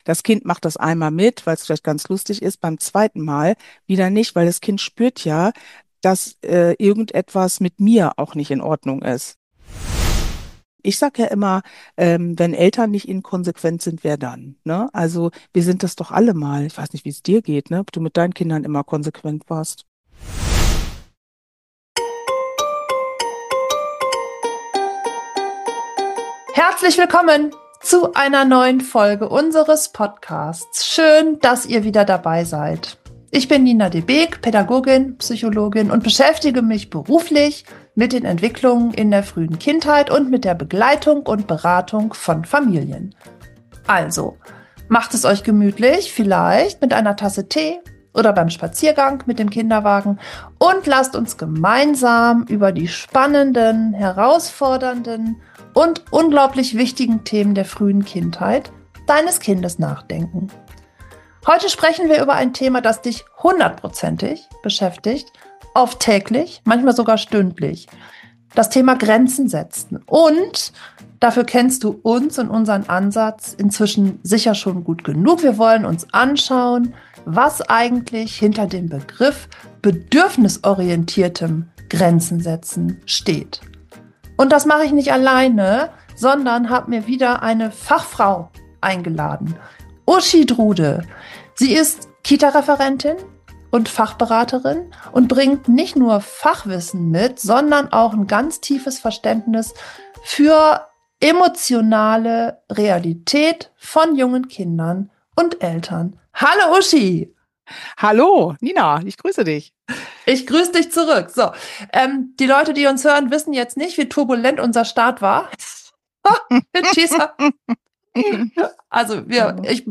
Ein informatives Gespräch